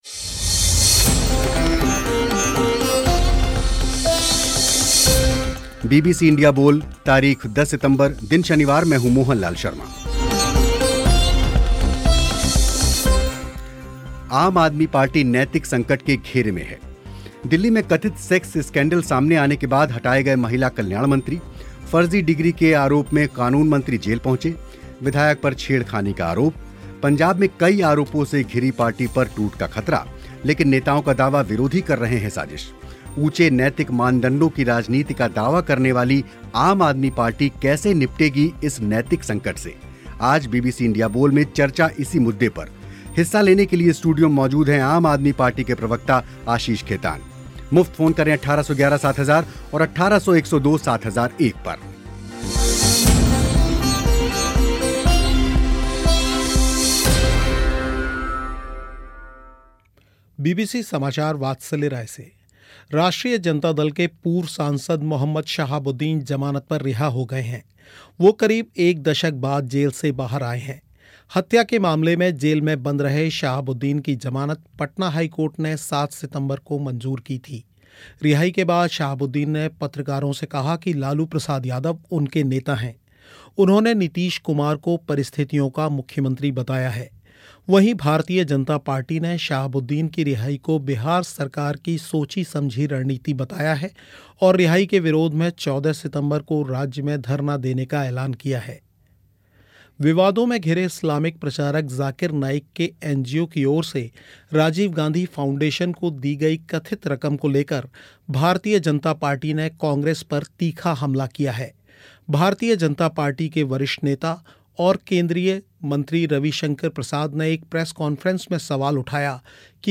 कार्यक्रम में इसी विषय पर चर्चा हुई. स्टूडियो में मौजूद थे आम आदमी पार्टी के प्रवक्ता आशीष खेतान